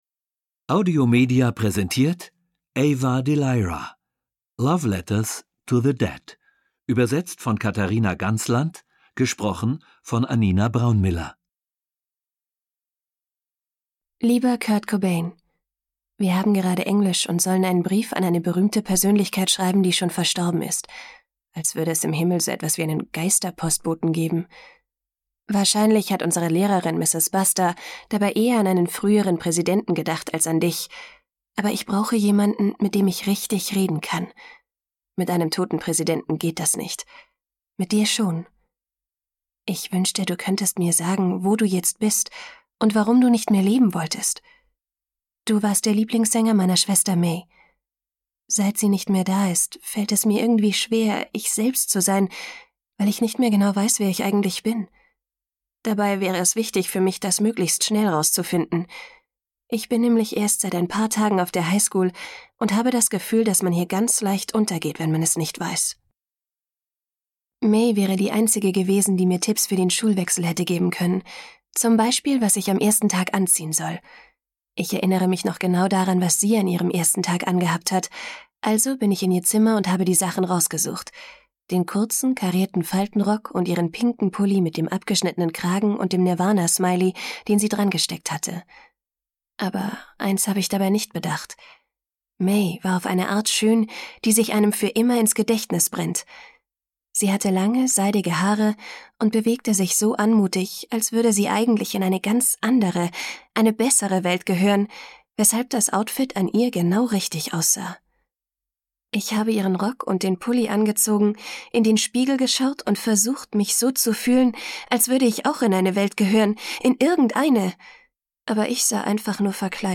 Schlagworte berühmte Persönlichkeiten • Briefe • Gedanken • Hörbuch; Lesung für Kinder/Jugendliche • Identitätsfindung • Schwester • Verlust